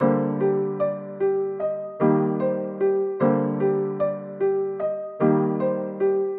描述：我做的钢琴，听起来像一个年轻的暴徒类型的节拍。
Tag: 150 bpm Trap Loops Piano Loops 1.08 MB wav Key : Unknown